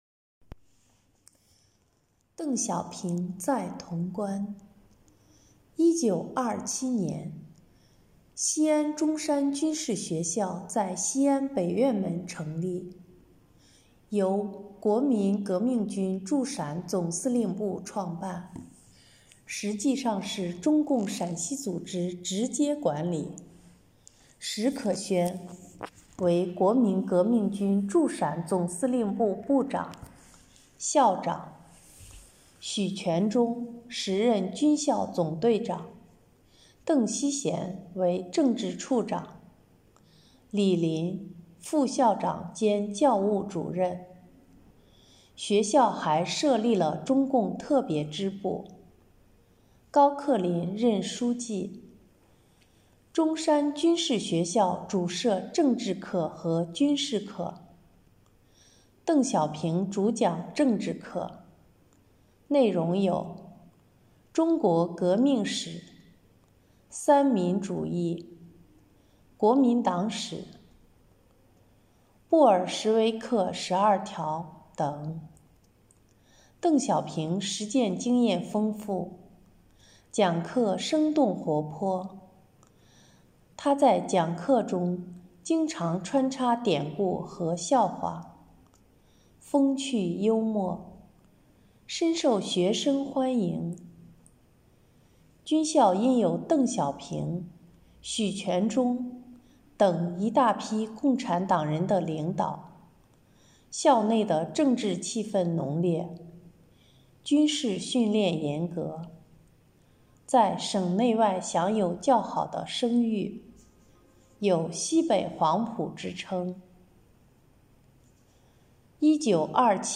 【红色档案诵读展播】邓小平在潼关